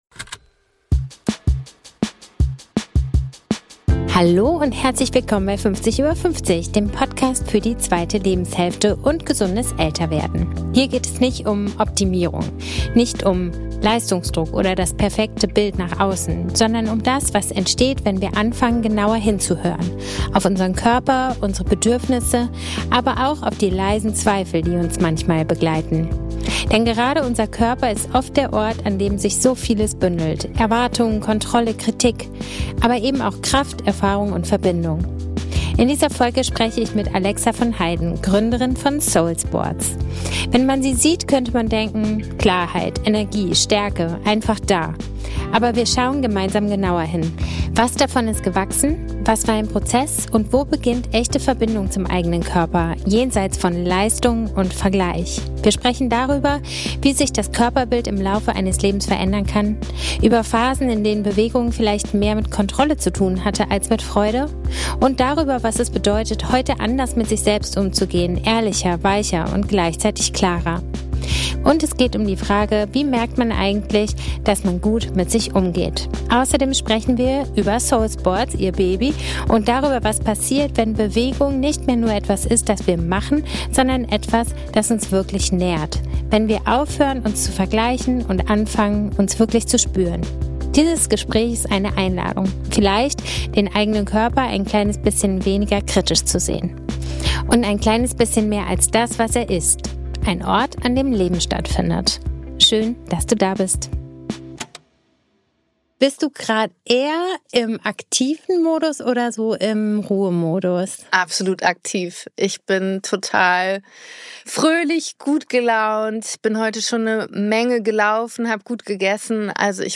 Dieses Gespräch ist eine Einladung.